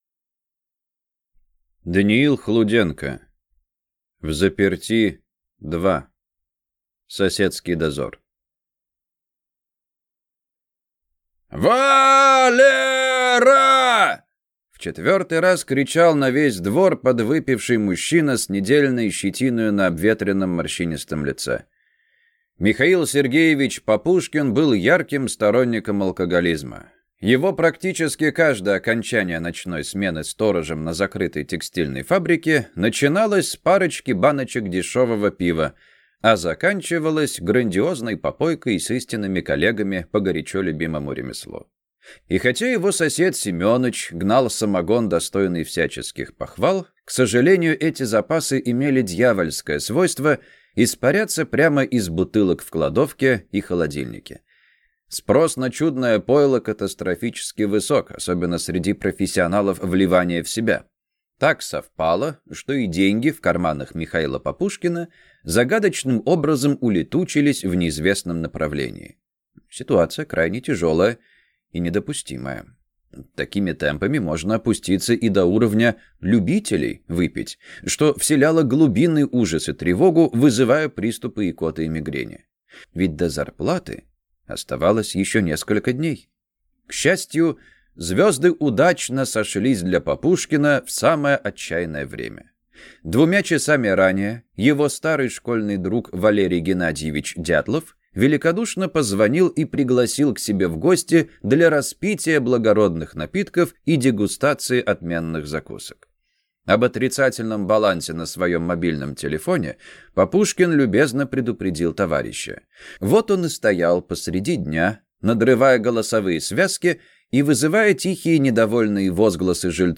Аудиокнига Взаперти 2. Соседский дозор | Библиотека аудиокниг